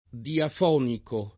diafonico [ diaf 0 niko ]